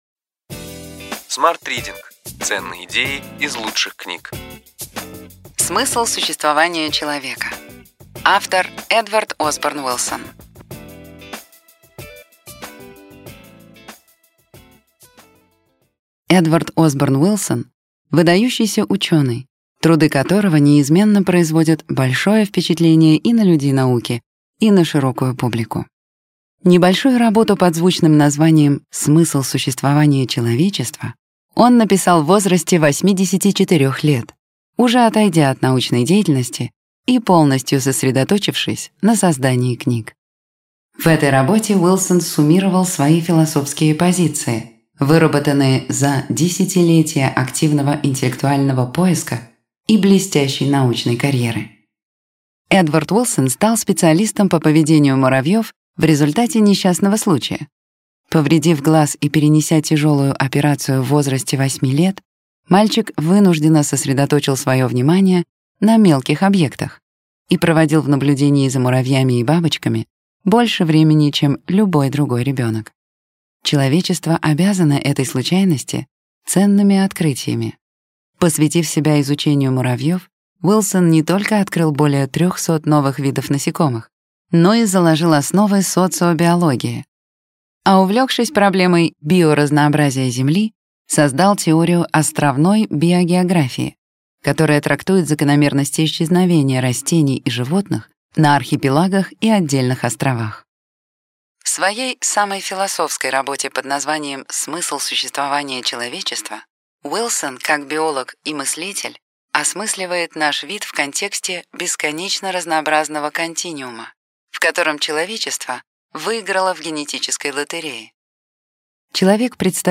Аудиокнига Ключевые идеи книги: Смысл существования человека. Эдвард Осборн Уилсон | Библиотека аудиокниг